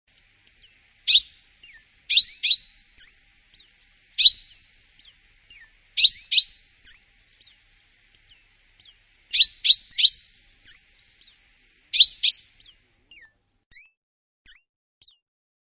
Buchfink
Sein Flugruf ist ein gedämpftes "jüp, jüp".
Ab März ertönt sein lauter Gesang, der mit einem Triller endet, aber selbst für Spezialisten nur schwer zu ,,übersetzen" ist: in etwa ,,zizizizjazjazoritiu-zip" oder ,,zipzipzip". Je nach Lebensraum gibt es auch regionale Dialekte im Ruf. zurück zur Übersicht >
buchfink.mp3